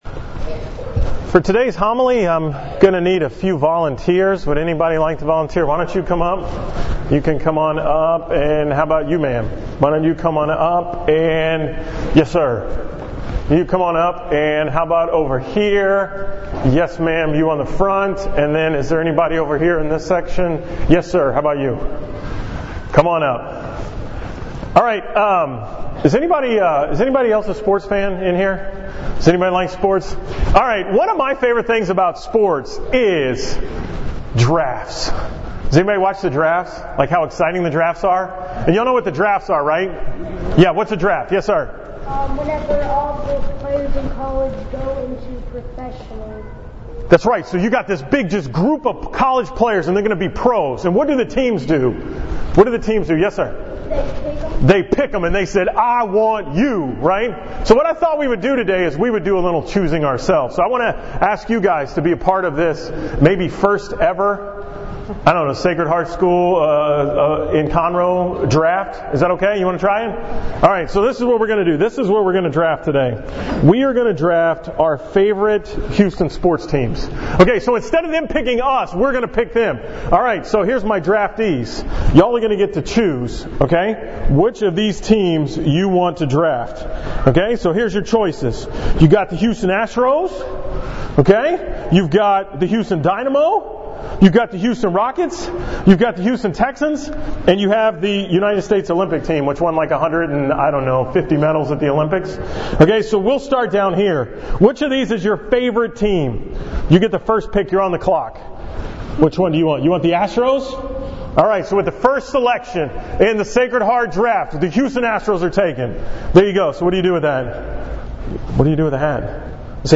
From the school Mass at Sacred Heart in Conroe on October 14, 2016